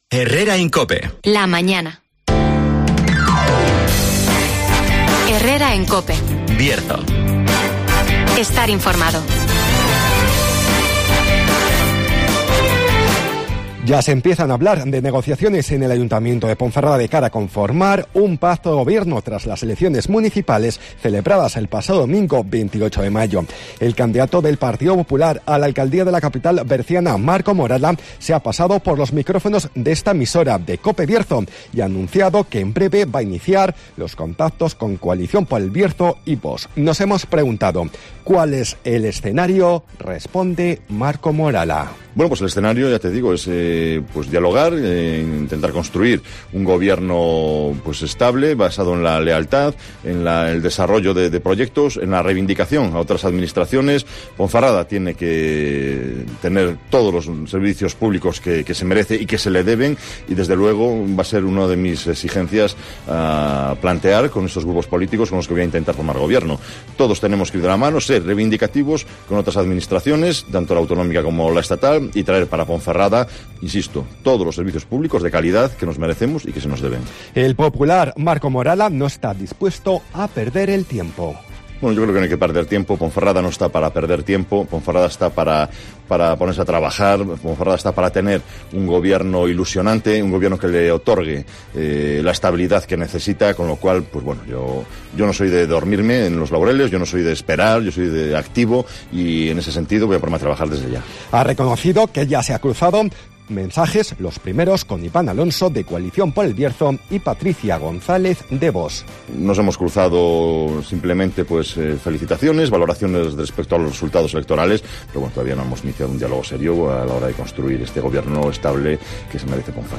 Entevista